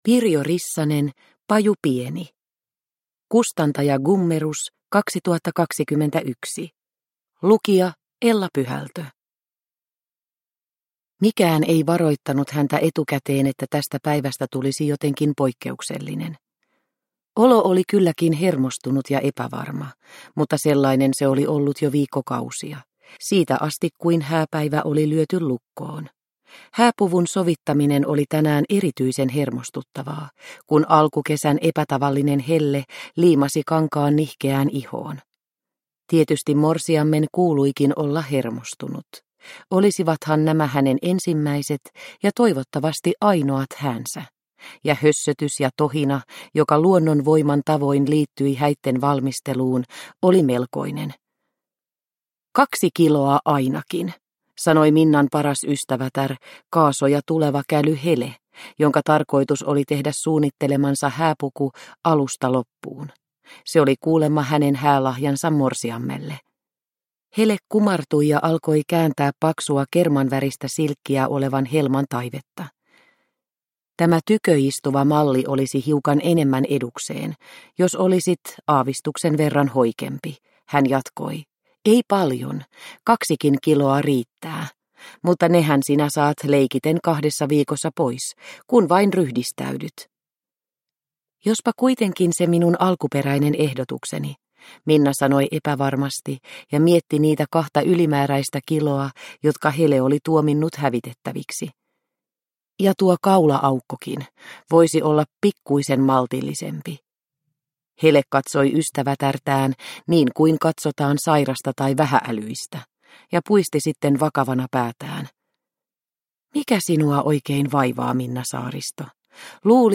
Paju pieni (ljudbok) av Pirjo Rissanen